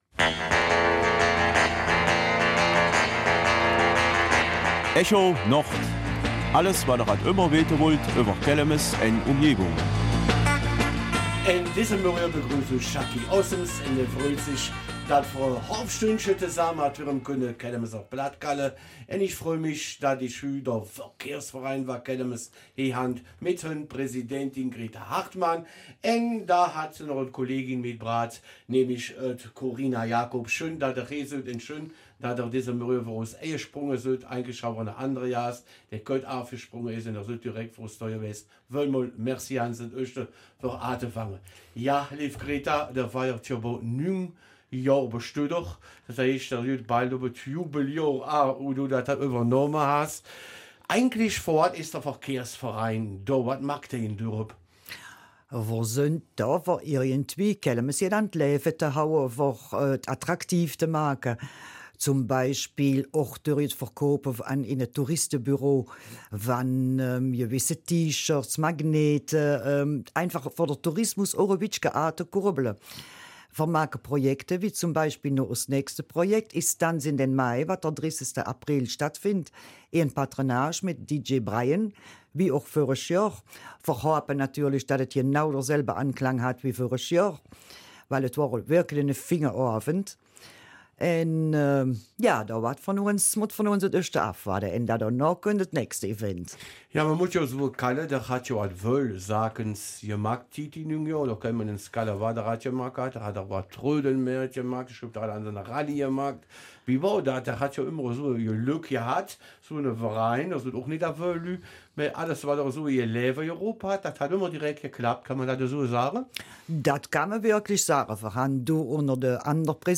Kelmiser Mundart: Neues vom Verkehrsverein Kelmis